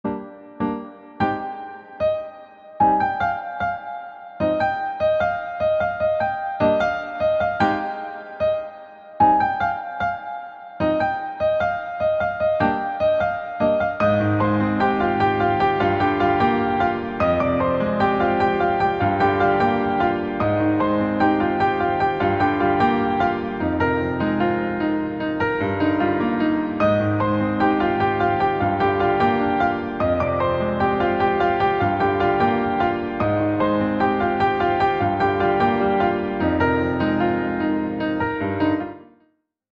• Качество: 128, Stereo
спокойные
без слов
красивая мелодия
клавишные
пианино